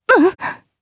Lewsia_B-Vox_Damage_jp_02.wav